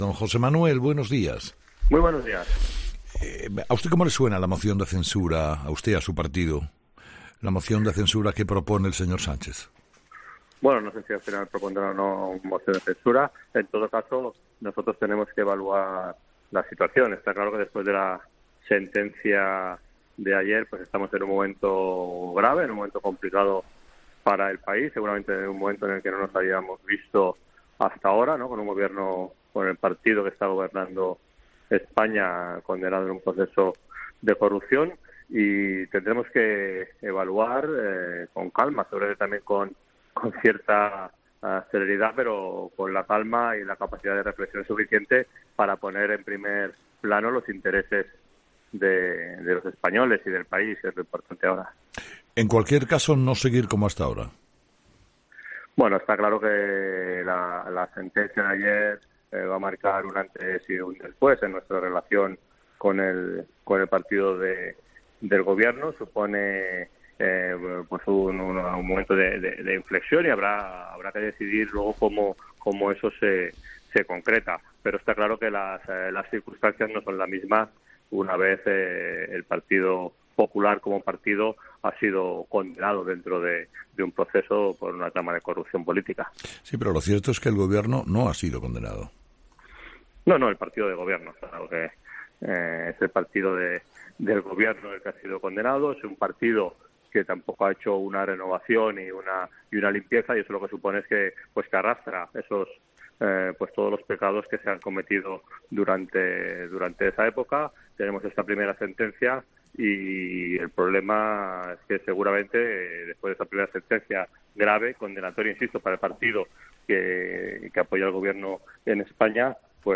José Manuel Villegas: "Habrá que evaluar si vamos a unas elecciones"